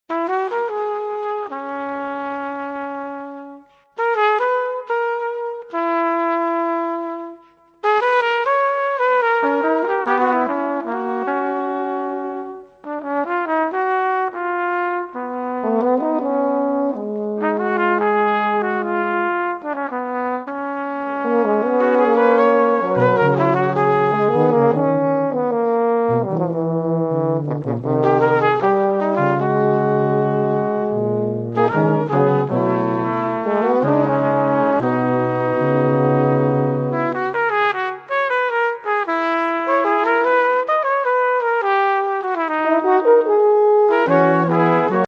Obsazení: 2 Trompeten, Horn und Tuba